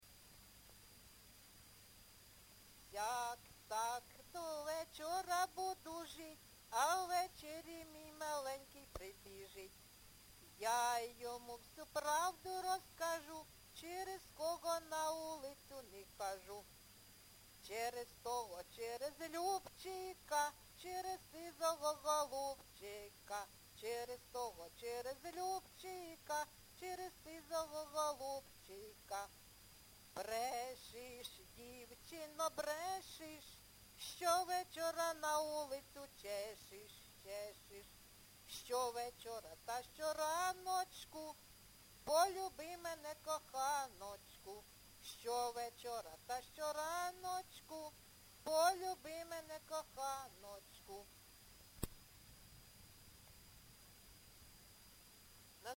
ЖанрЖартівливі
Місце записум. Ровеньки, Ровеньківський район, Луганська обл., Україна, Слобожанщина